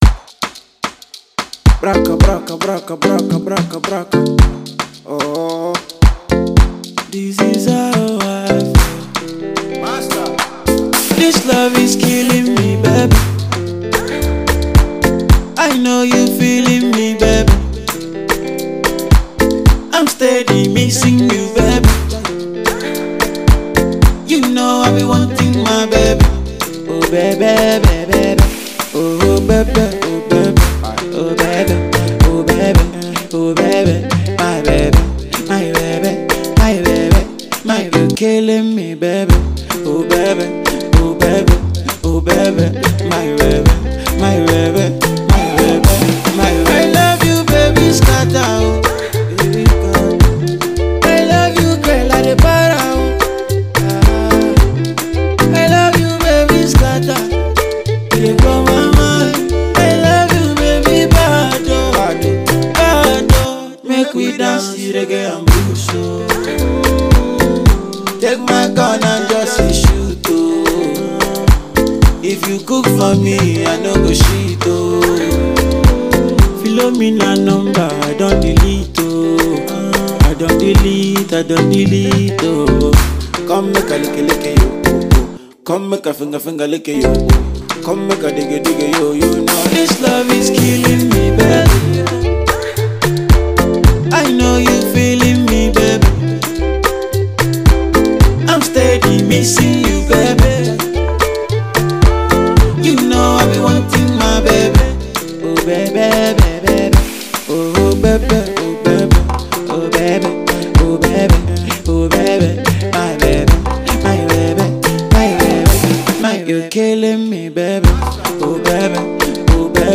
love song
Get ready to dance the Reggae and Blues, This Bliss!